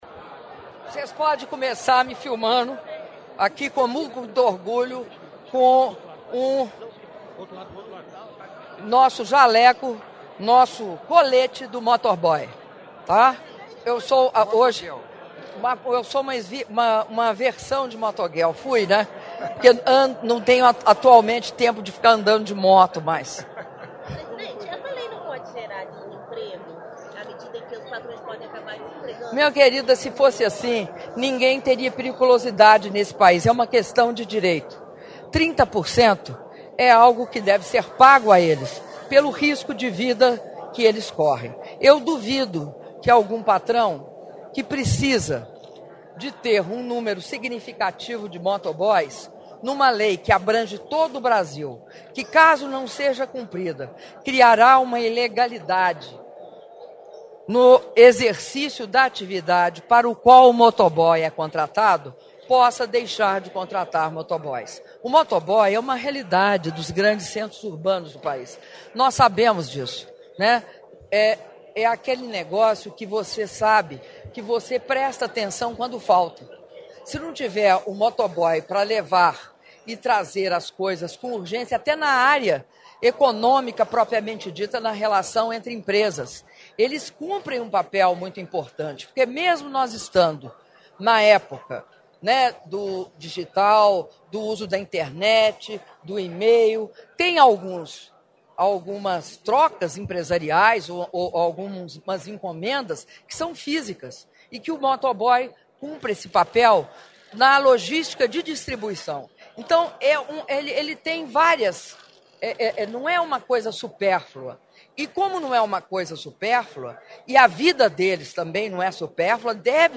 Áudio da entrevista coletiva concedida pela Presidenta da República, Dilma Rousseff, após a cerimônia de sanção da lei que inclui na Consolidação das Leis do Trabalho (CLT) a periculosidade da atividade dos trabalhadores em motocicleta - Brasília/DF (05min29s)